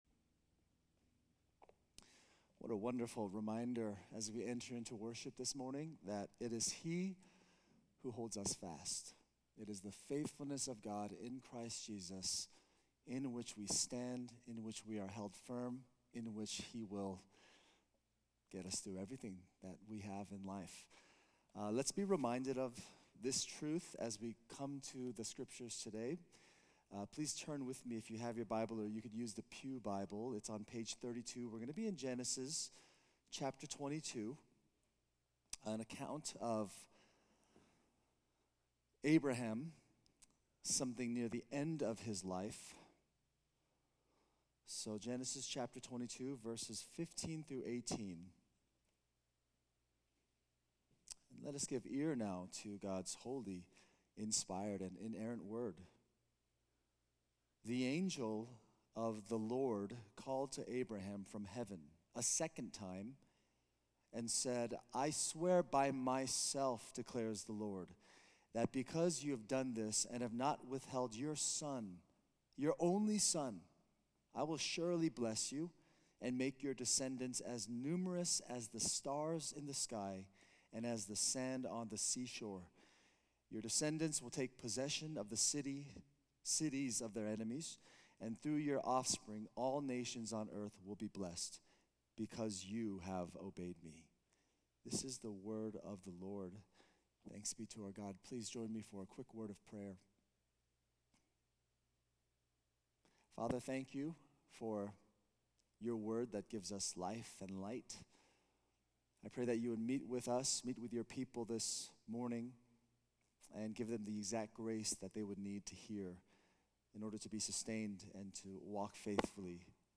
A message from the series "We are the Church."